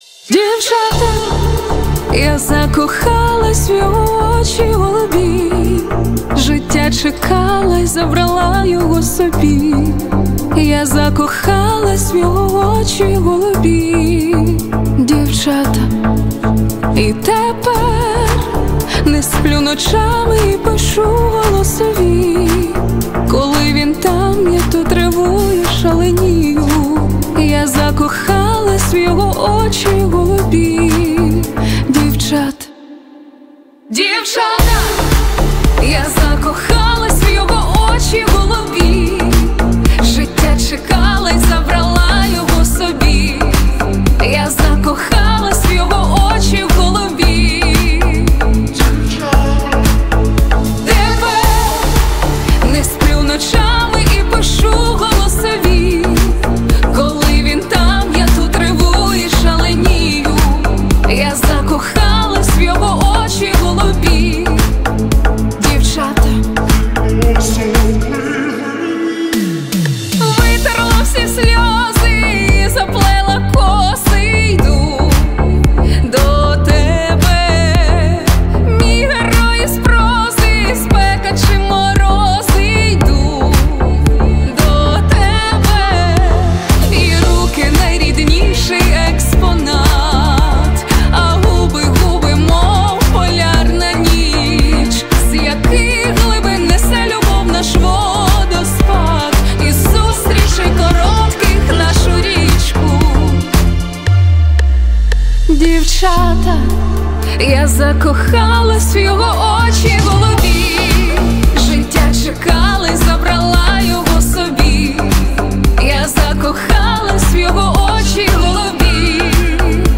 • Жанр: Поп